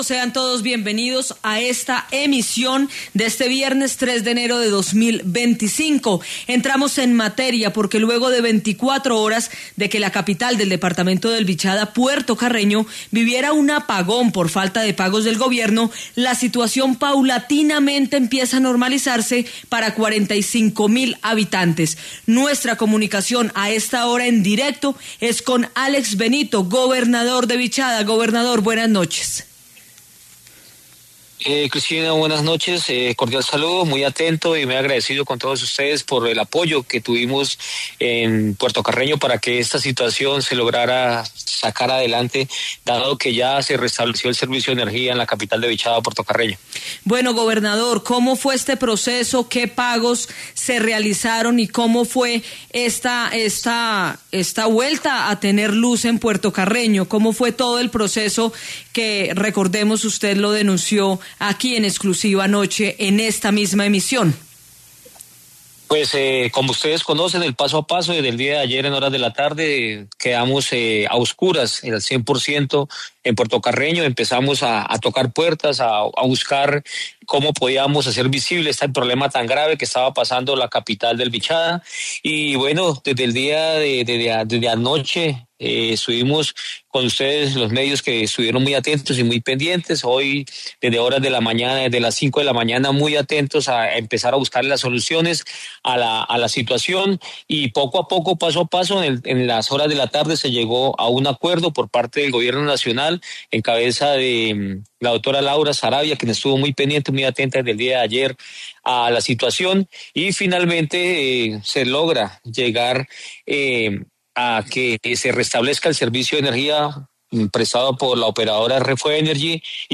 En diálogo con Caracol Radio durante el noticiero de la noche de este viernes 3 de enero, el gobernador de Vichada, Alex Benito, habló sobre el restablecimiento del servicio de energía en Puerto Carreño y sus peticiones al Gobierno Nacional para que un nuevo apagón no se vuelva a presentar en el departamento.